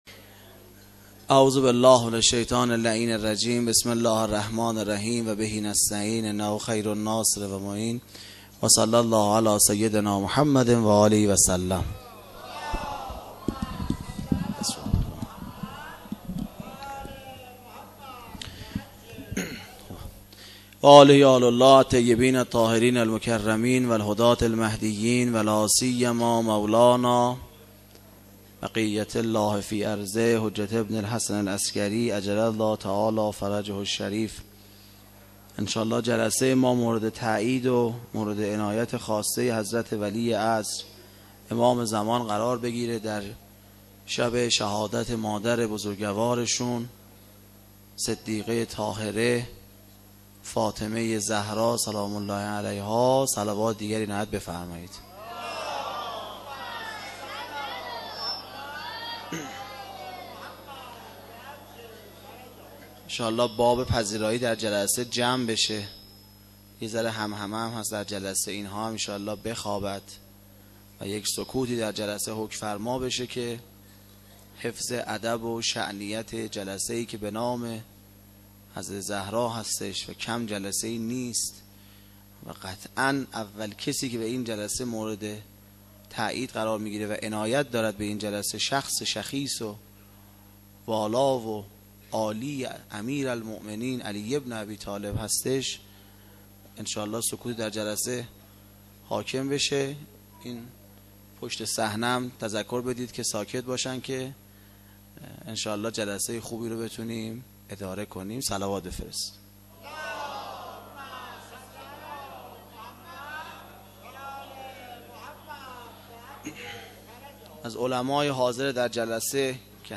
سخنرانی شب اول فاطمیه اول